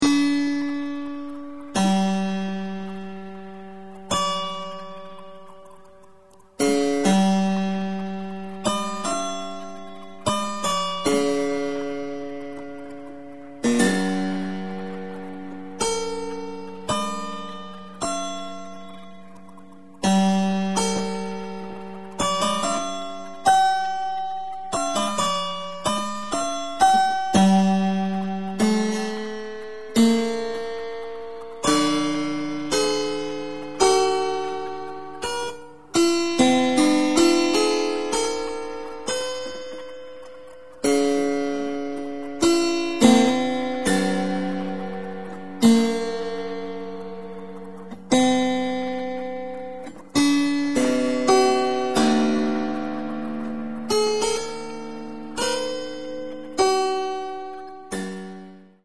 This spinet sounds transparantly and softly, just like the other historical instruments (harpsichord, virginal, clavechord, lute), and also just like ethnical string instruments ( at most I like the 'oud' from  Iran).
Now, I play the virtual prepared spinet, capturing and transmitting the acoustic sound through the sound effects processor (KAOSSPAD, Korg). The result is an organic fusion of acoustic and electronic timbres. The mix-patch is a gamelan-like sound with a digital vs acoustic dialogue in the attack and sustain of the sound bathing in a natural resonating environment.
the mp3-excerpt of a P-ART prepared spinet composition 2006.(© P-ART)
spinet2110Kaossexcerpt.mp3